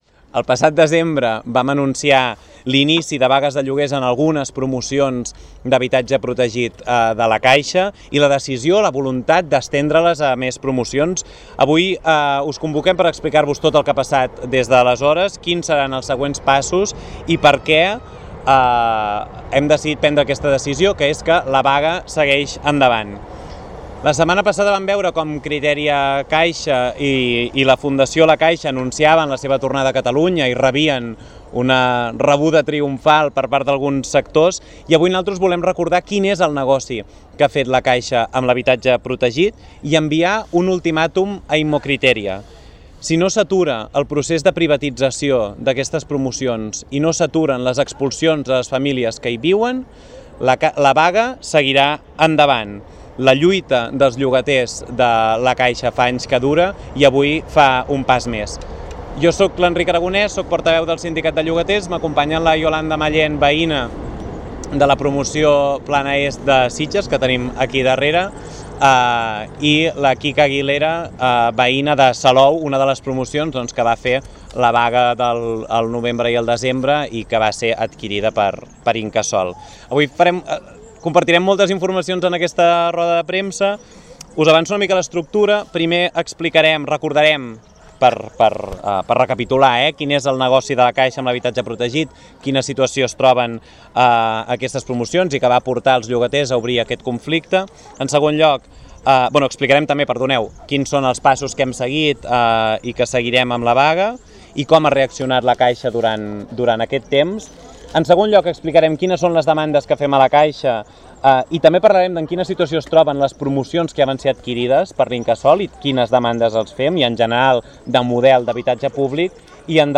Us oferim, íntegra, la roda de premsa efectuada aquest matí